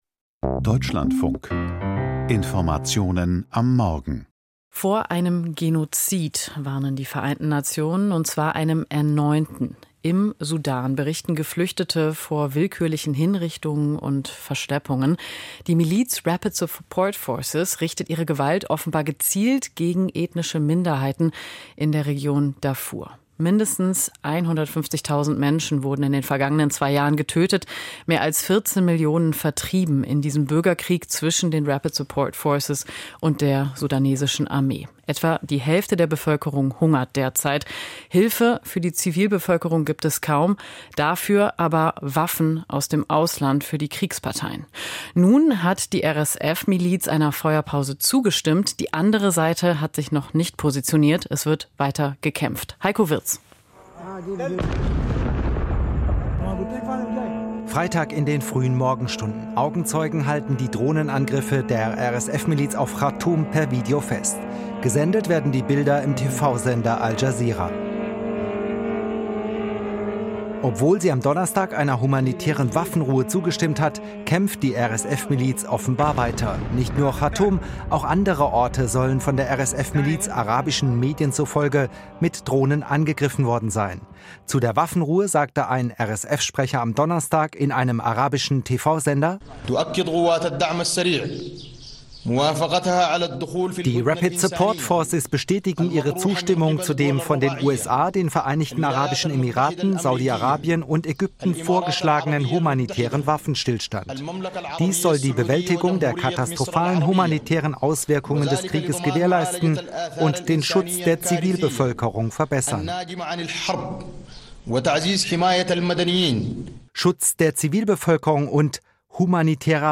Sudan-Krieg - Internationale Einflussmöglichkeiten, Interview